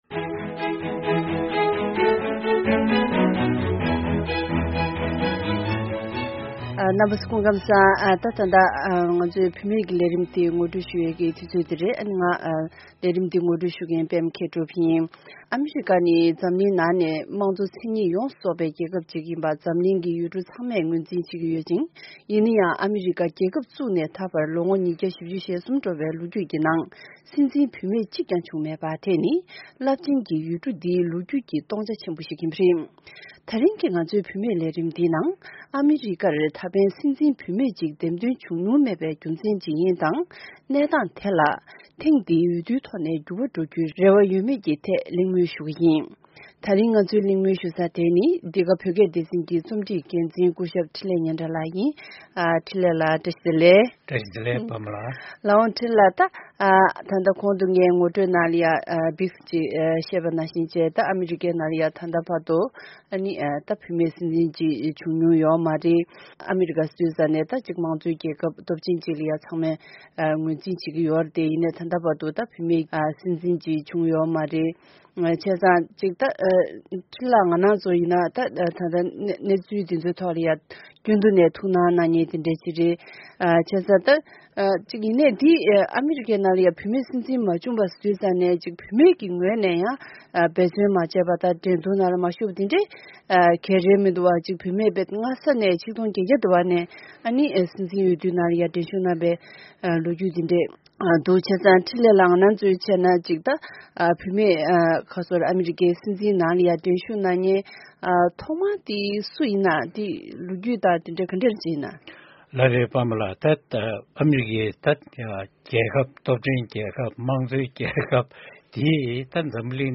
གླེང་མོལ་ཞུ་ཡི་རེད།།